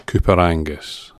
Coupar Angus (/ˈkʊpər/